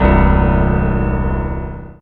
55y-pno05-g2.aif